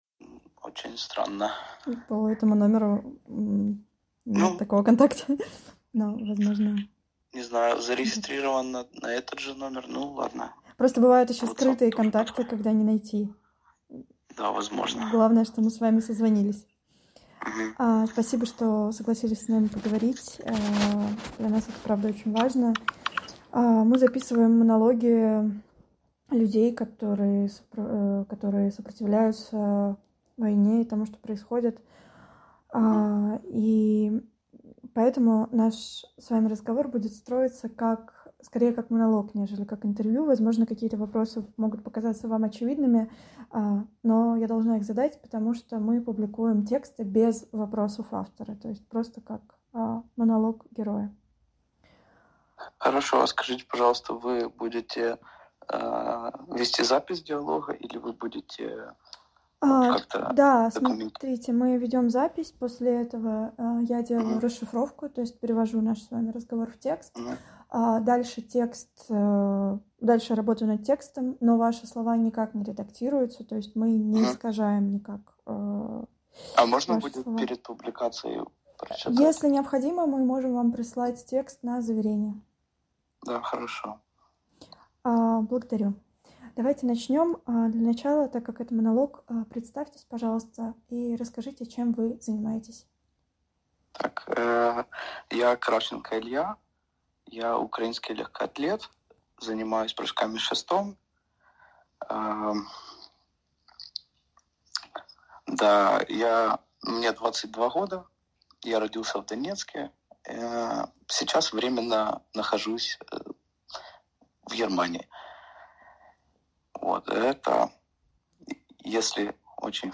Интервью Предыдущая Следующая Часть 1 / 1 Скачать аудио -10 +10 1 1,5 2 Все части Часть 1 NaN:NaN:NaN Расшифровка Расшифровка Скопировать расшифровку 00:12 Благодарю.